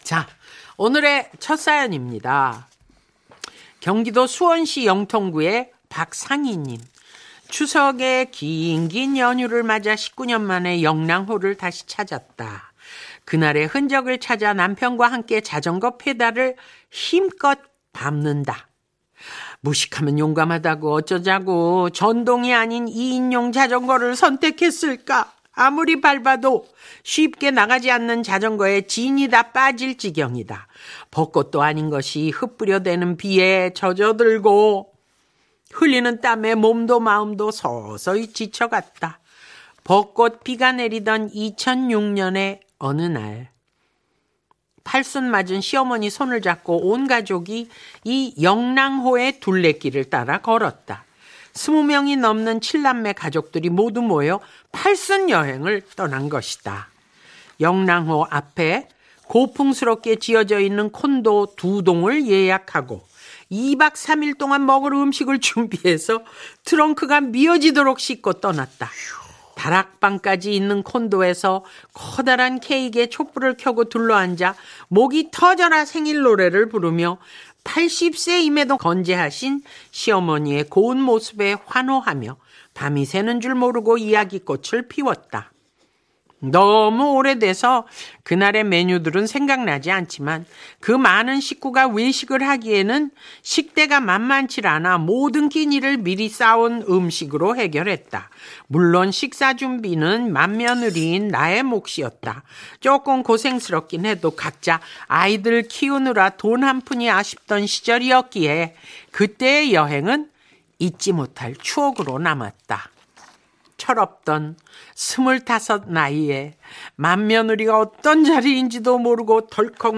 그 글을 95.9 MBC라디오 여성시대에 사연으로 보냈다. 예고도 없이 지난 금요일 양희은. 김일중 님의 맛깔스러운 목소리로 소개되는 영광을 누렸다.
다시 한번 두 분의 끝내주는 호흡으로 부족한 글을 빛나게 해 주셔서 진심으로 감사드립니다.